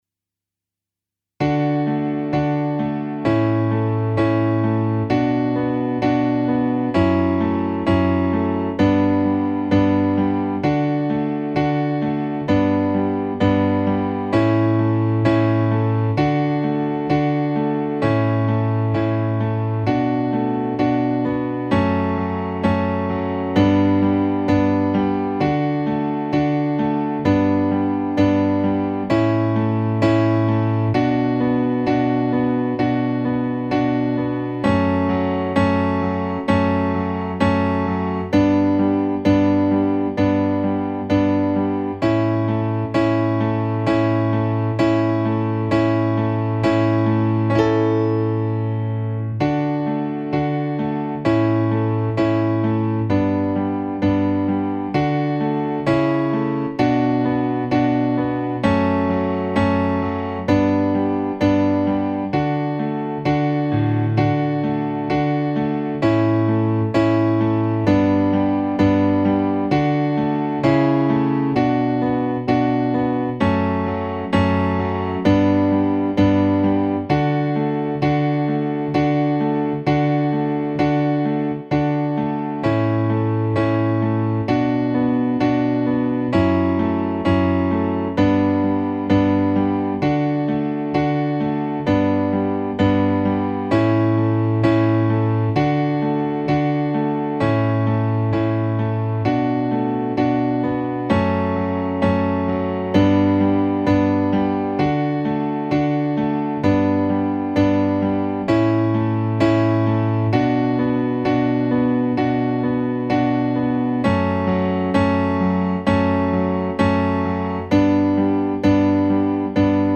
ピアノのみインスト